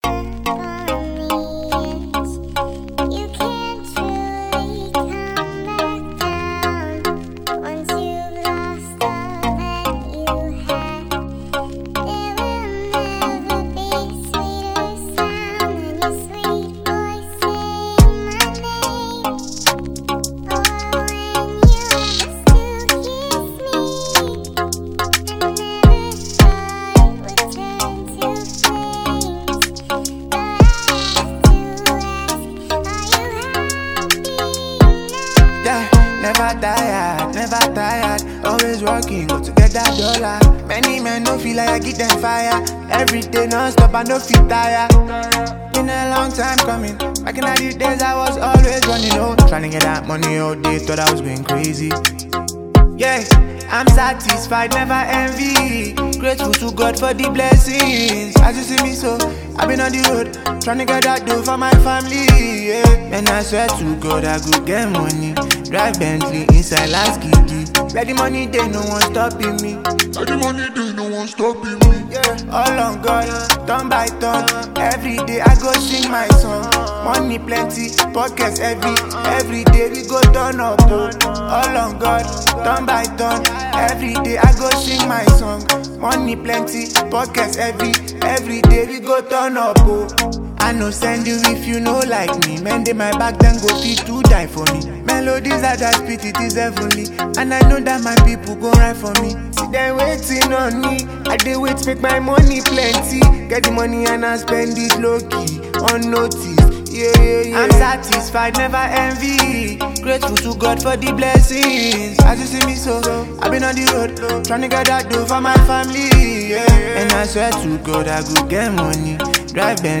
Here comes another sweet melody